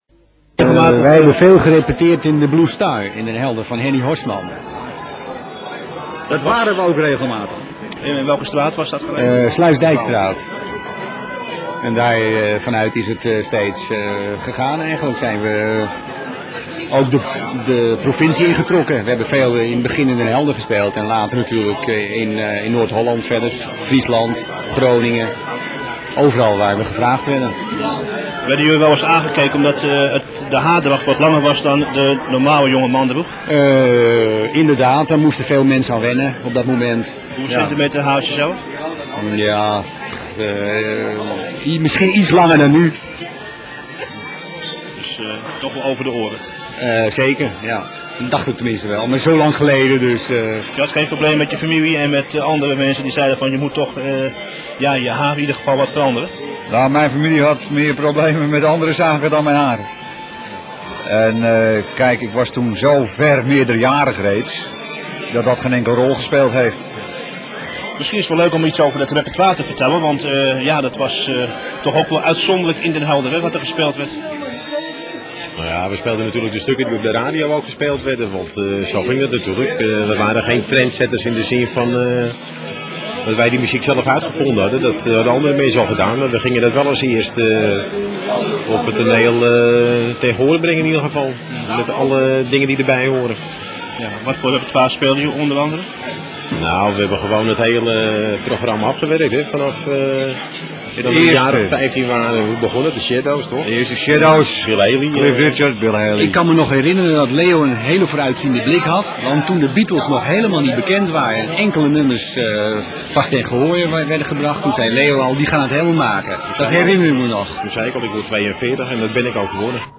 Black Devils interview 11 oktober 1986.